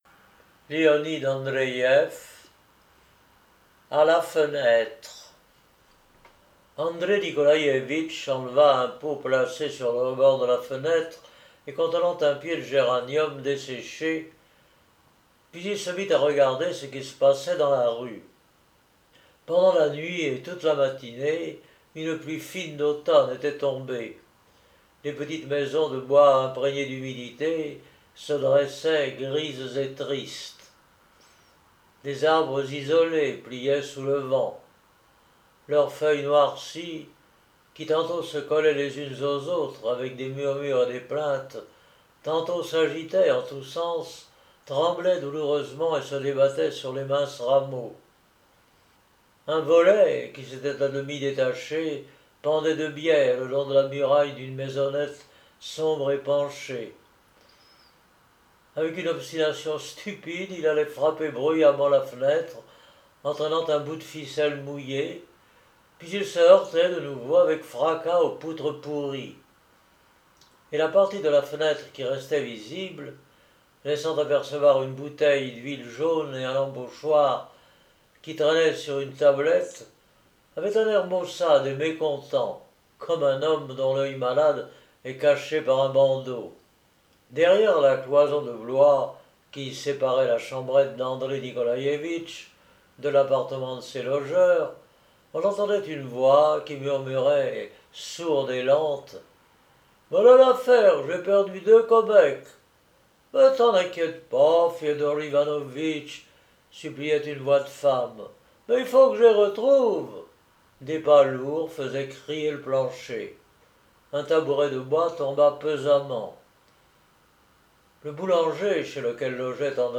ANDREÏEV Léonid – Livres Audio !
Genre : Nouvelles « Après avoir soupiré en pensant à la bêtise humaine